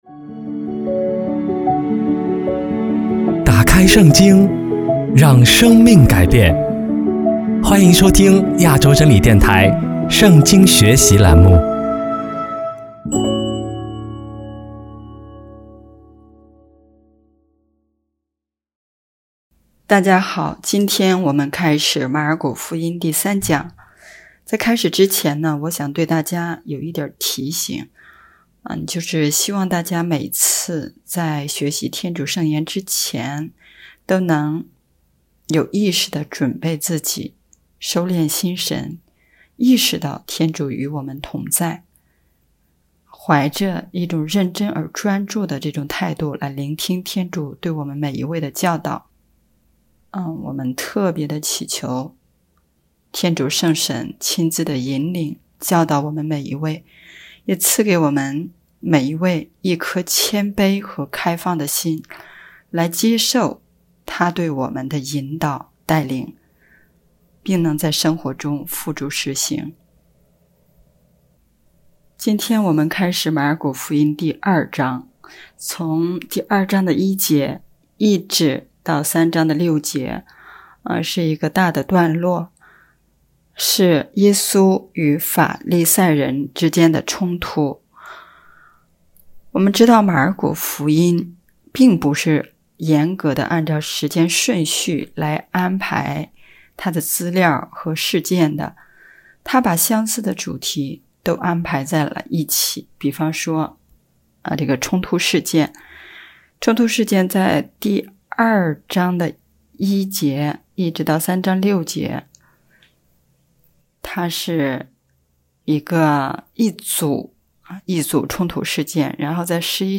【圣经课程】|马尔谷福音第三讲